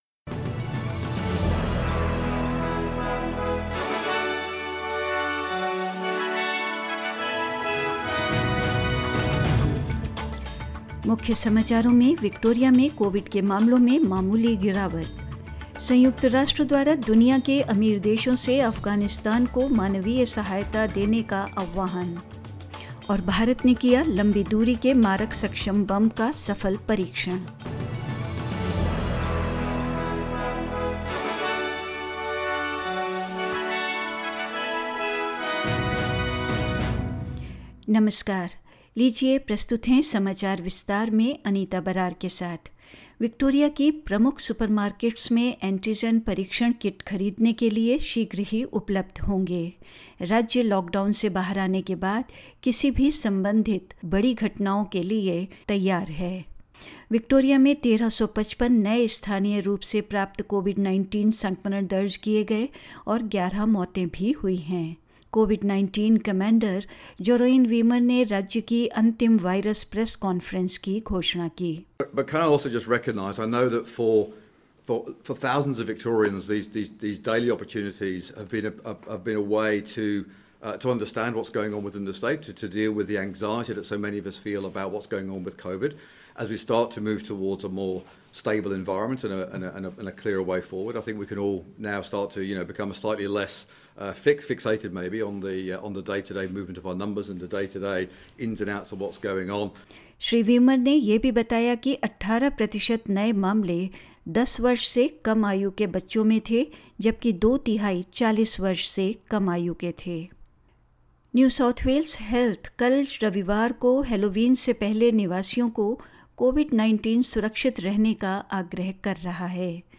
In this latest SBS Hindi News bulletin of Australia and India: Victoria records a slight drop in Covid cases as the state reunites Melbourne and regional areas; The United Nations has asked the world's richest countries to give more in humanitarian aid to Afghanistan; India successfully tested indigenously developed long-range bomb (LRB) and more news